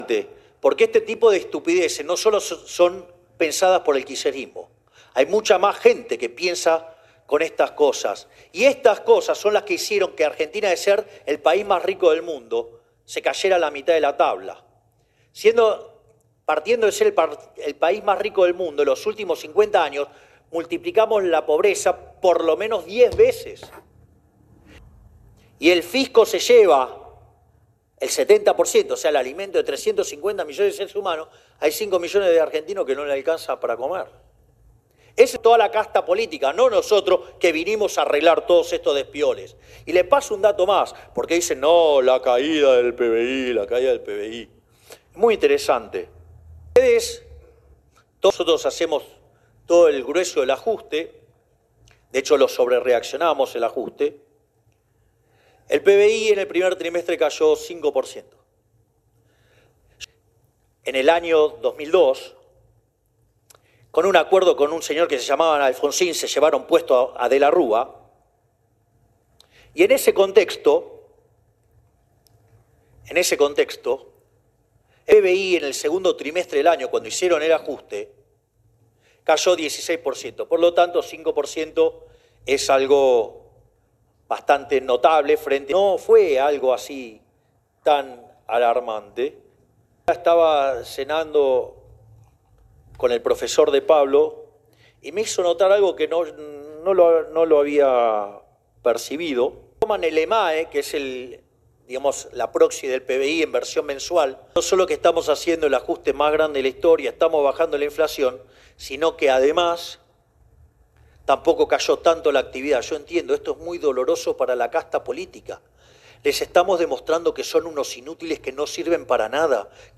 El presidente brindó un discurso en el marco de la Cumbre del Instituto Argentino de Ejecutivos de Finanzas (IAEF) en la provincia de Mendoza.
Audio. Milei brindó un discurso en Mendoza y habló sobre la realidad de la economía